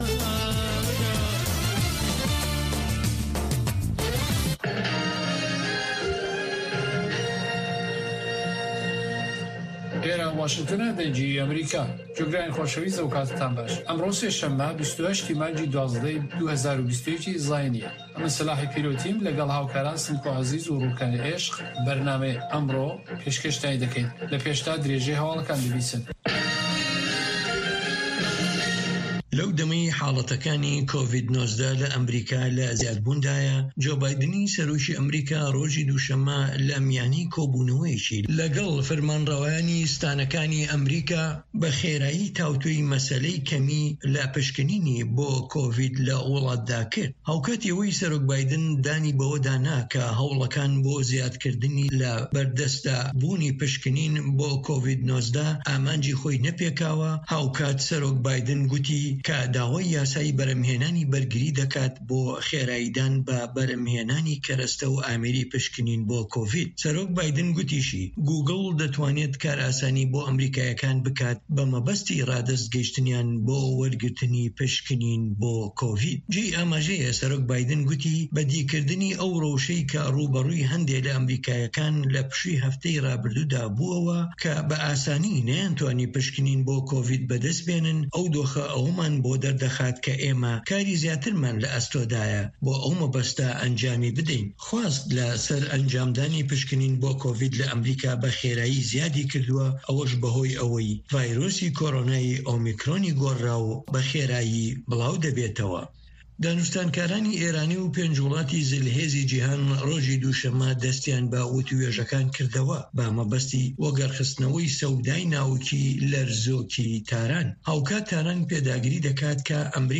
هه‌واڵه‌کان، ڕاپـۆرت، وتووێژ